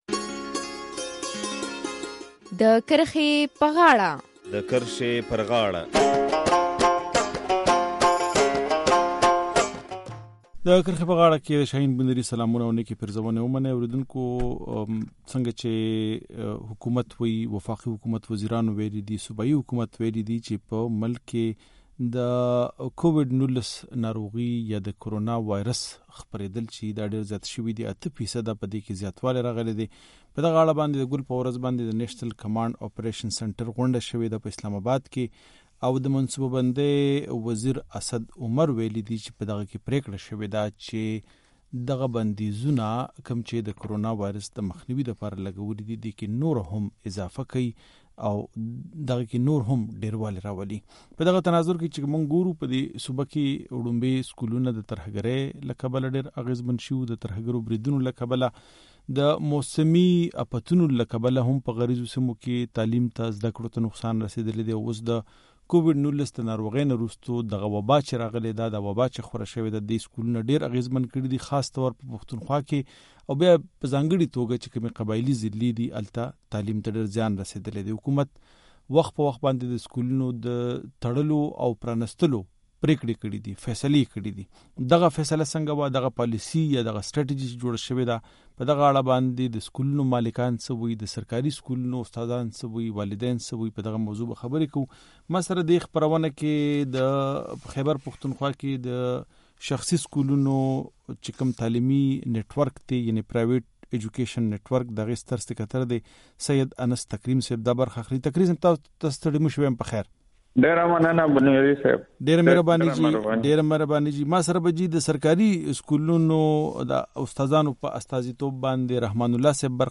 استادان او کارپوهان وايي د وبا له کبله زده کړو ته ډېر زیان رسېدلی دی. د کرښې پر غاړه خپرونه کې پرې بحث کوو.